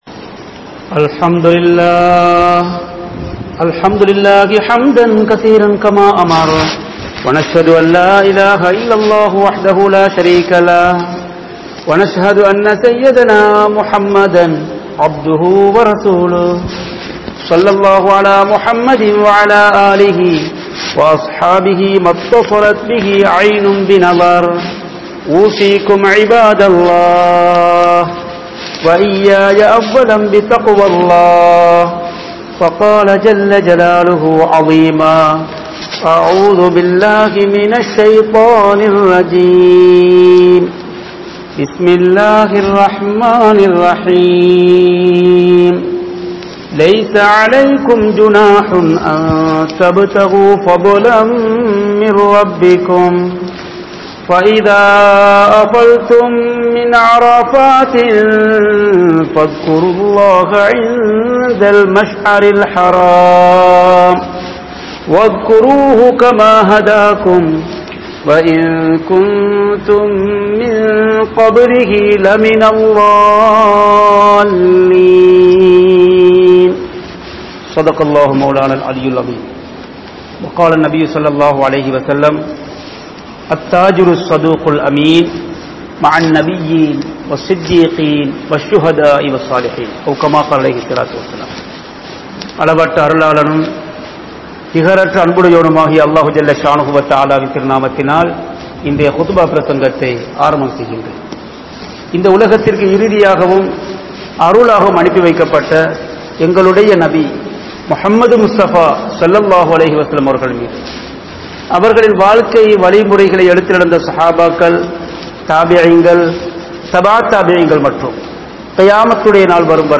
Viyafaarien 12 Thanmaihal (வியாபாரியின் 12 தன்மைகள்) | Audio Bayans | All Ceylon Muslim Youth Community | Addalaichenai